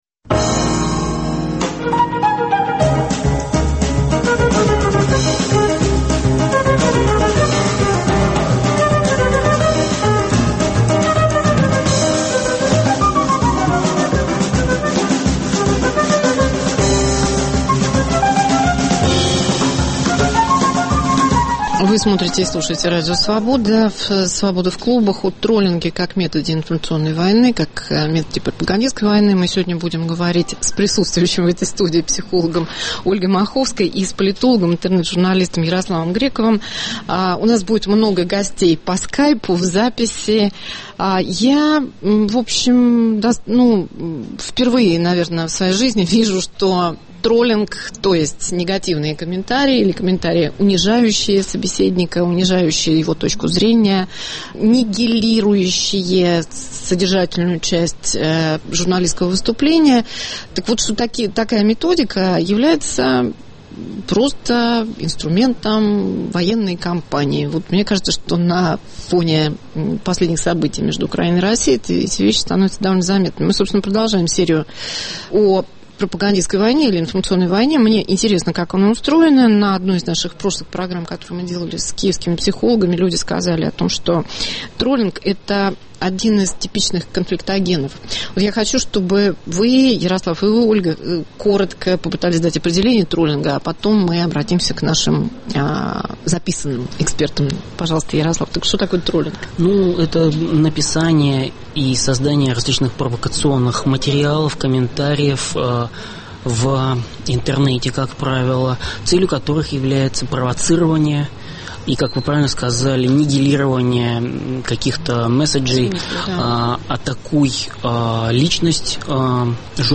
популярный блогер и интернет-аналитик Антон Носик